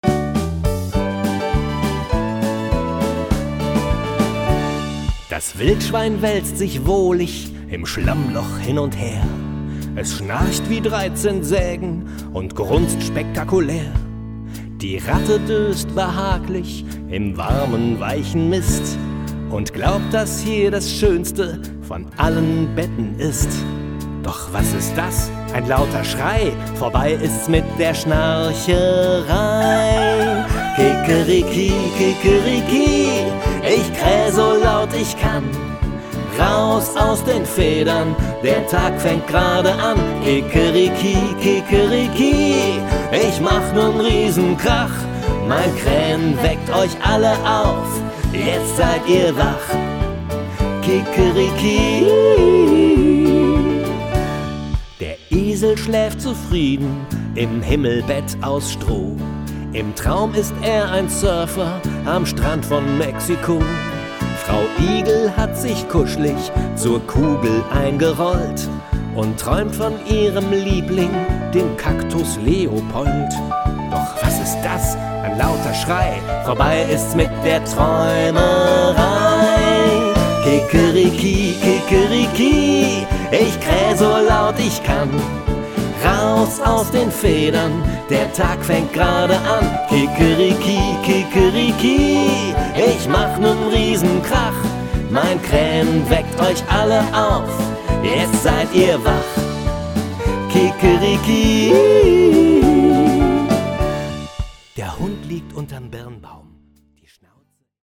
Aber unser jodelnder Hahn ist ein besonders sympathisches Exemplar dieser Spezies.